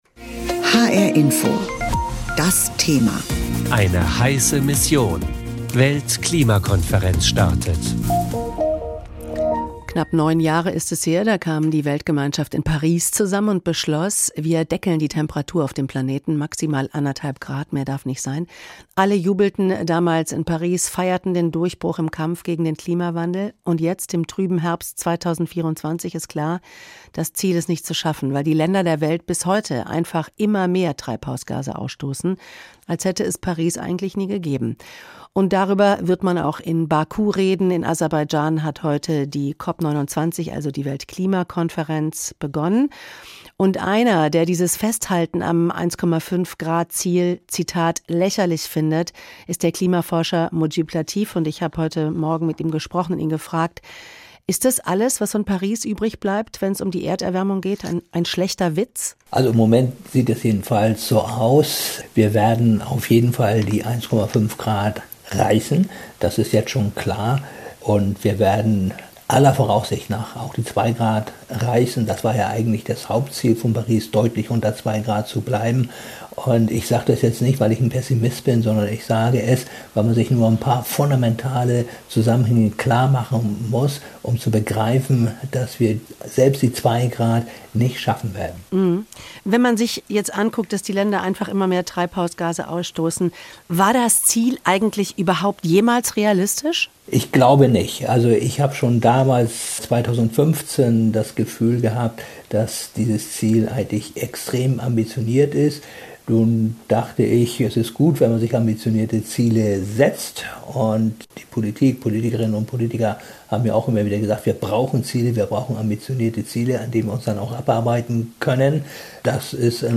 Im Interview spricht er darüber, was auf der Weltklimakonferenz in Baku mindestens erreicht werden muss.…